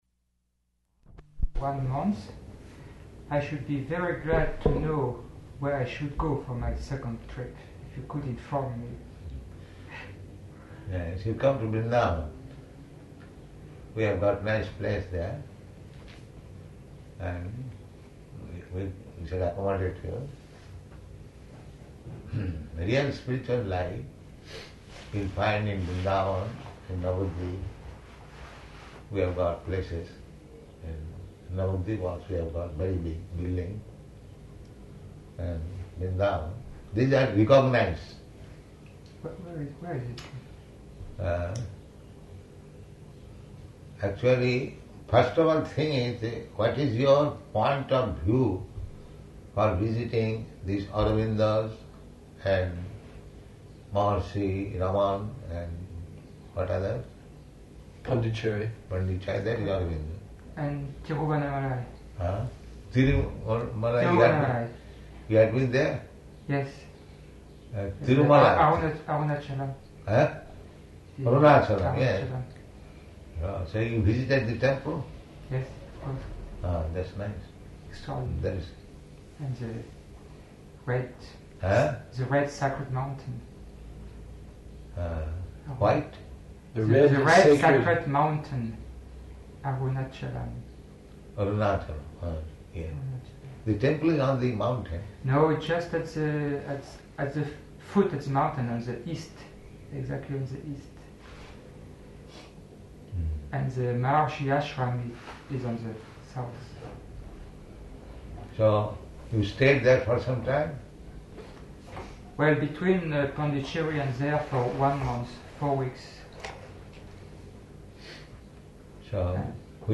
-- Type: Conversation Dated: June 5th 1974 Location: Geneva Audio file